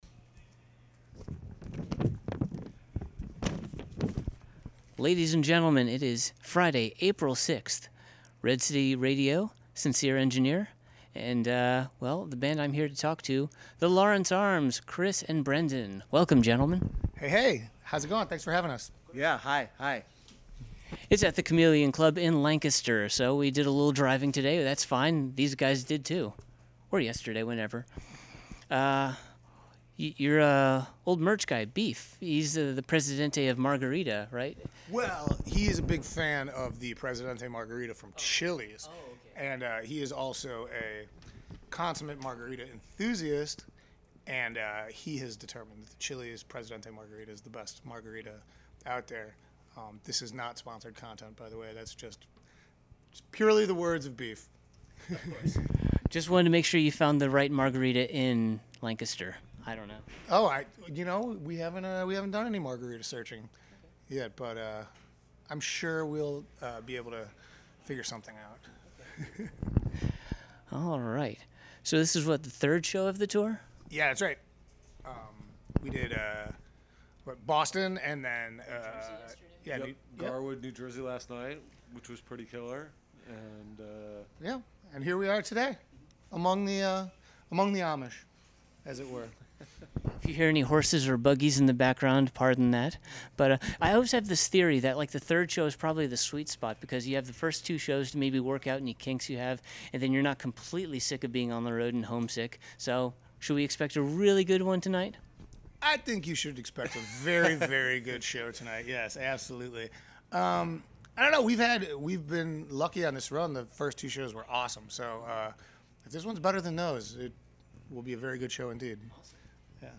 Exclusive Interview: The Lawrence Arms ‘Are the Champions of the World’ (Ep 82)
82-interview-the-lawrence-arms.mp3